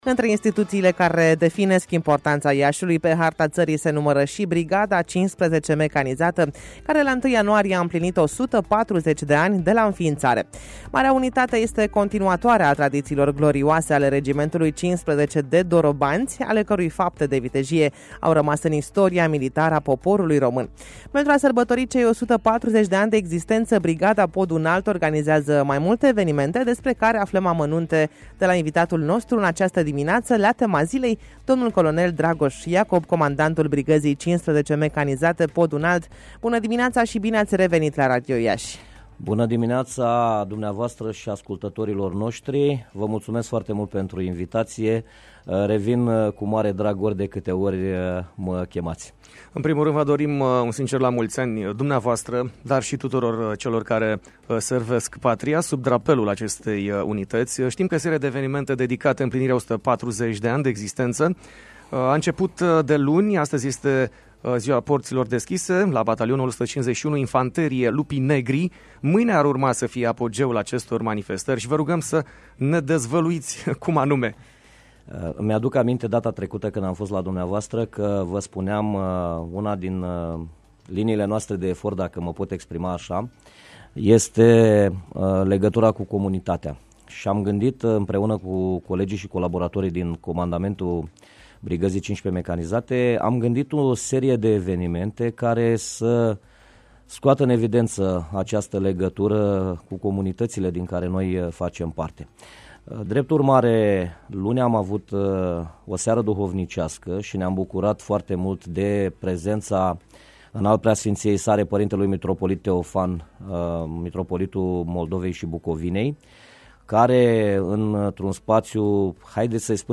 Înregistrarea interviului, mai jos: